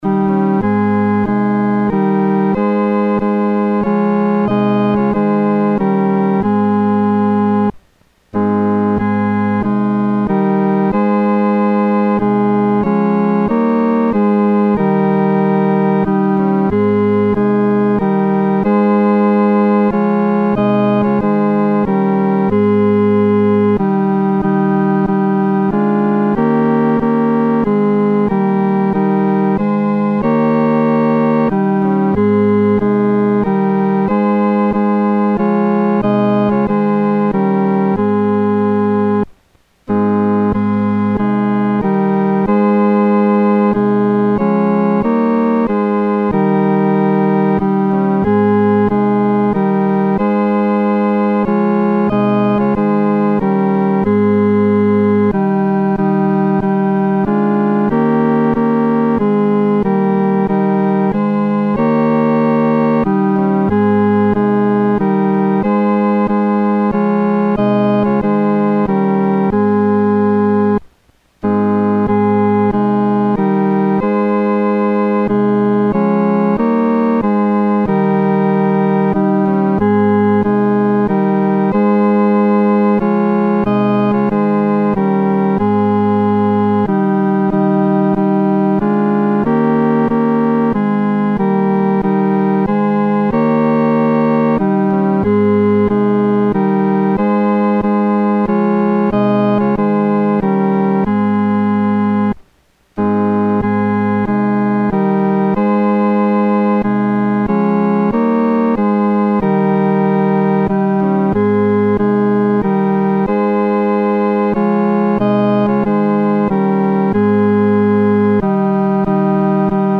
独奏（第三声）
来敬拜荣耀王-独奏（第三声）.mp3